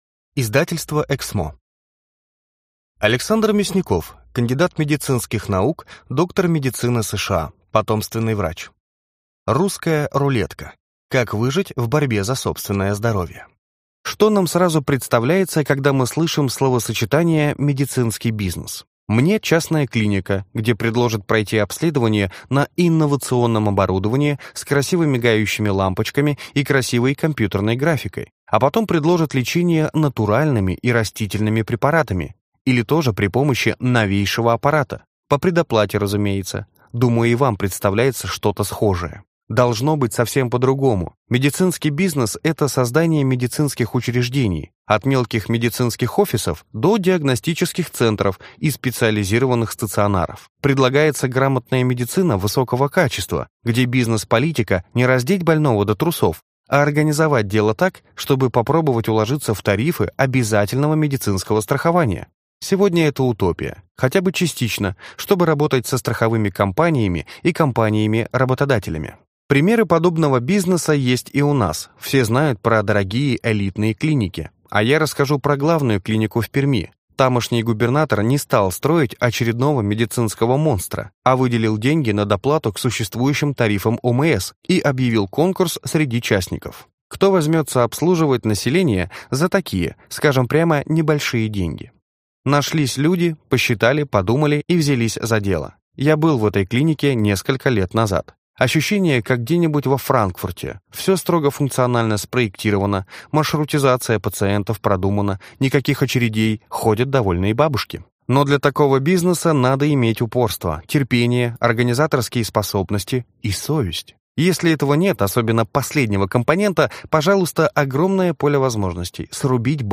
Аудиокнига Русская рулетка. Как выжить в борьбе за собственное здоровье | Библиотека аудиокниг